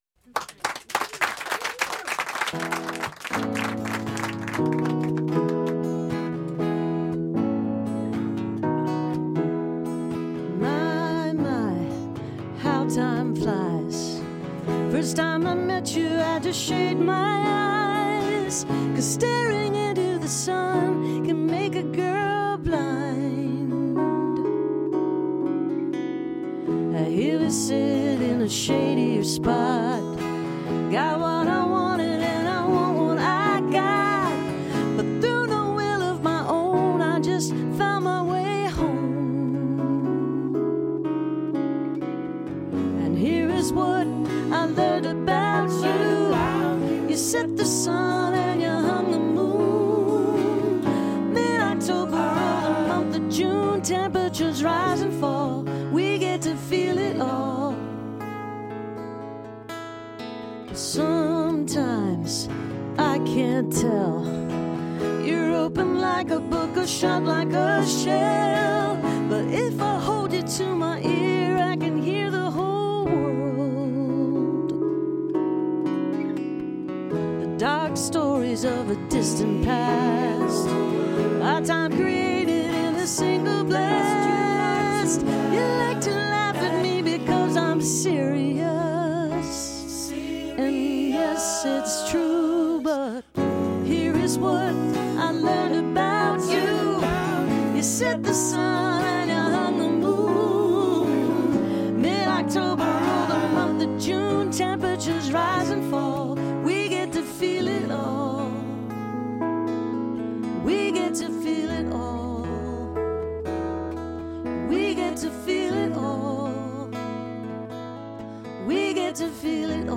(recorded from a webcast)